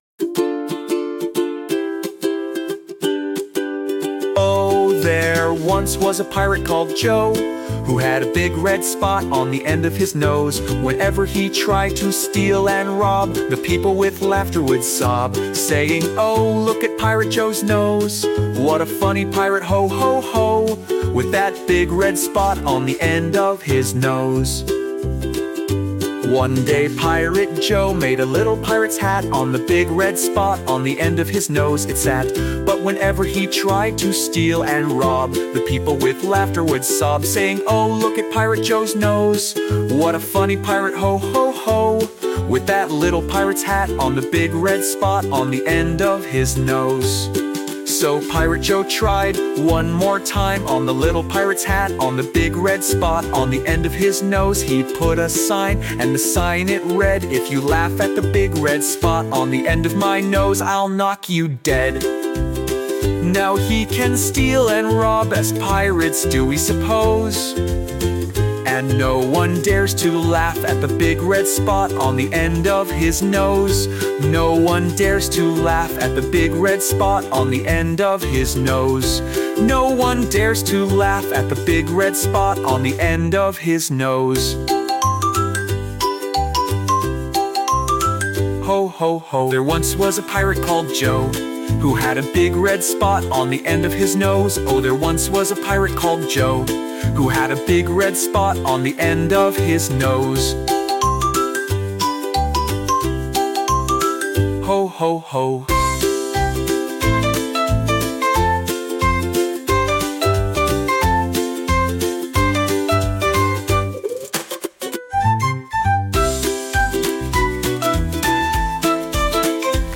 playful, rhyming children’s song